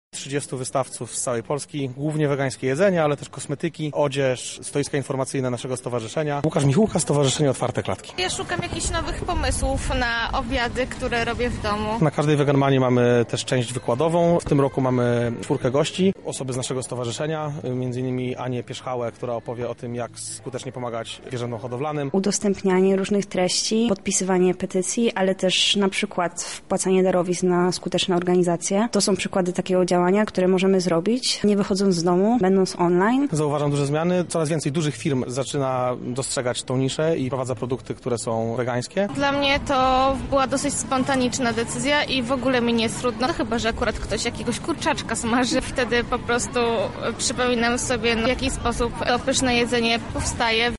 Rozmawiała z nimi nasza reporterka: